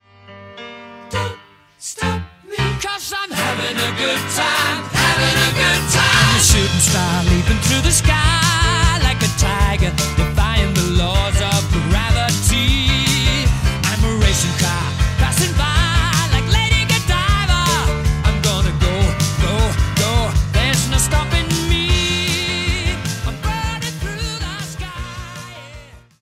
Cette musique rock est très rythmée et énergique.